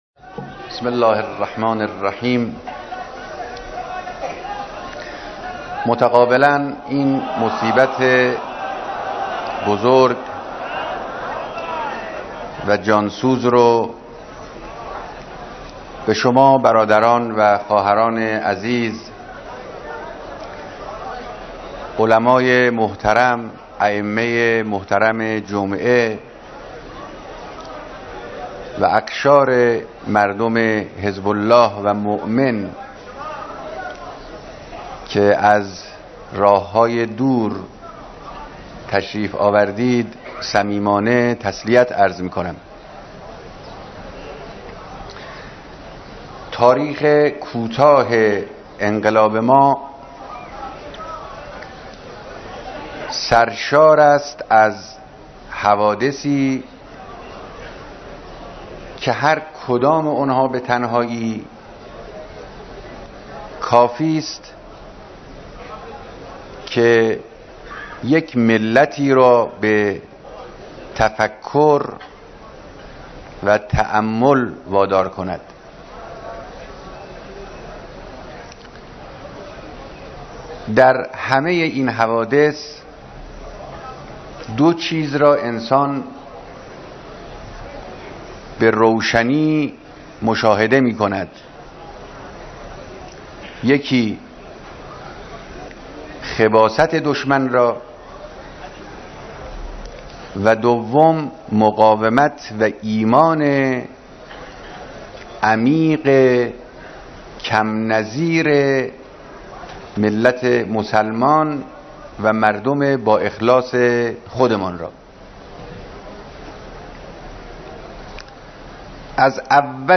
بیانات رهبر انقلاب در مراسم بیعت مسئولان و مردم استان‌های یزد و کهگیلویه و بویر احمد